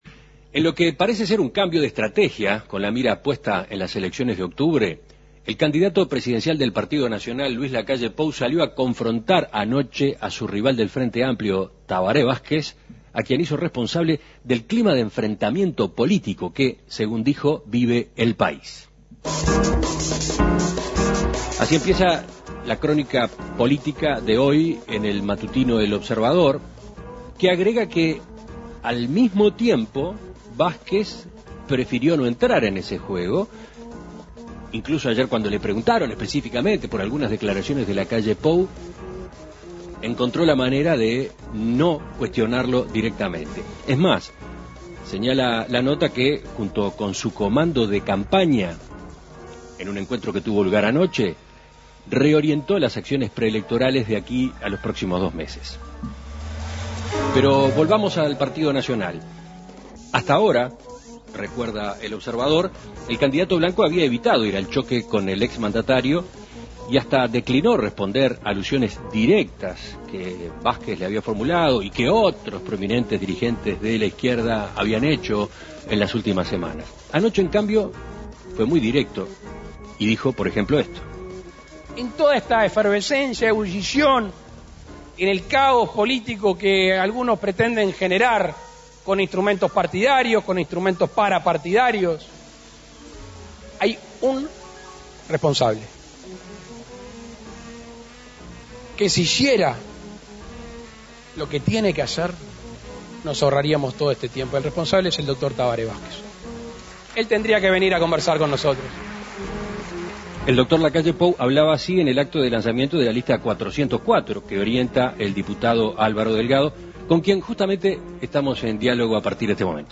En Perspectiva dialogó con el diputado Álvaro Delgado sobre el lanzamiento de esta lista y el rumbo de la campaña.